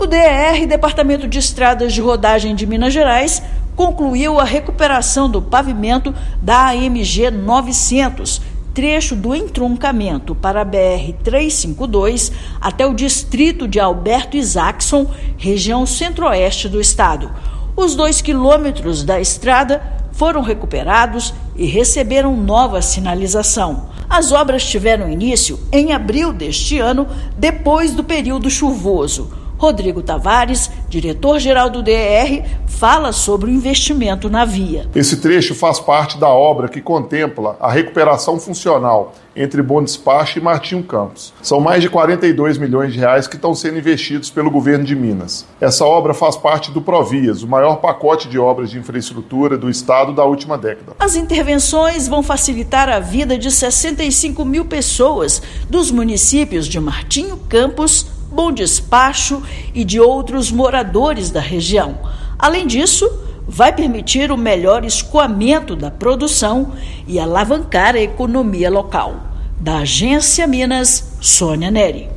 Cerca de 1.500 mineiros serão beneficiados com a conclusão das obras na AMG-900, no trecho entre a BR-352 e o distrito de Alberto Isaacson. Ouça matéria de rádio.